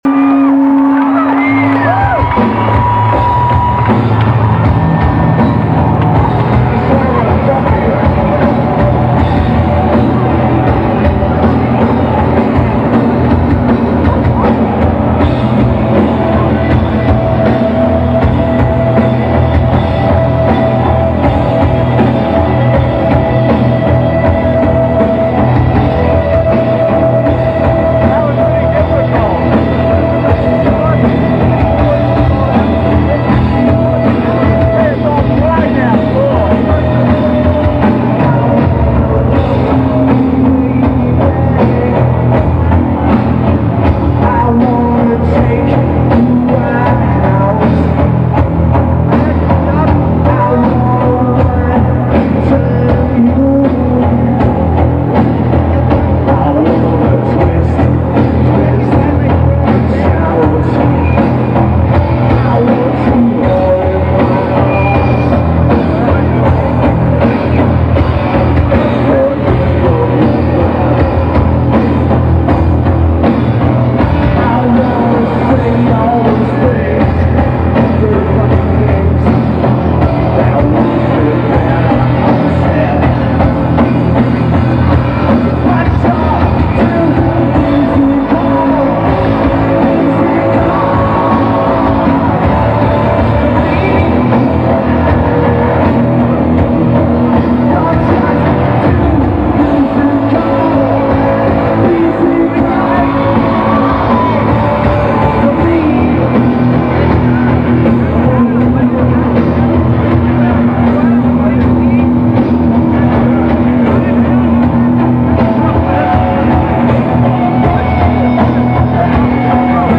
Shoreline Amphitheater
Lineage: Audio - AUD (Sony ECM-990 + Panasonic RQ-L335)